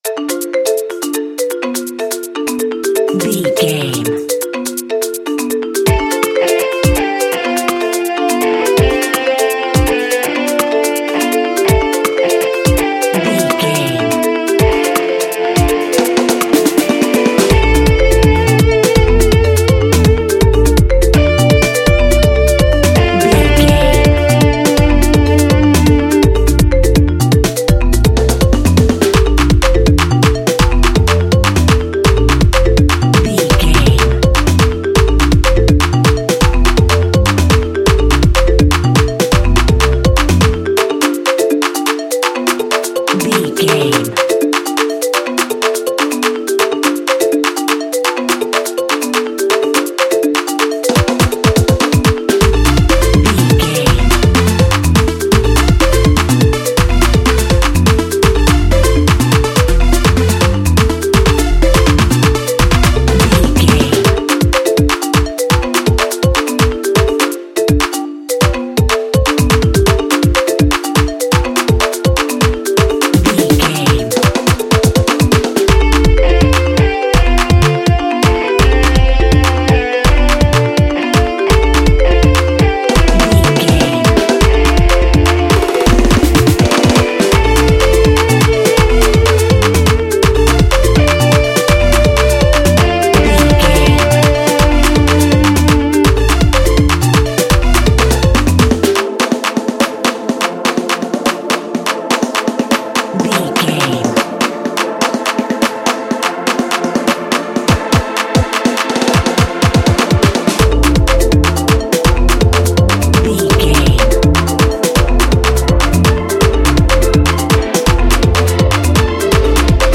Its relentless tempo fuels dynamic, carefree energy.
Fast paced
Uplifting
Ionian/Major
Fast
energetic
festive
Rhythmic
Pulsating